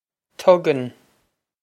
Tugann Tug-in
Pronunciation for how to say
This is an approximate phonetic pronunciation of the phrase.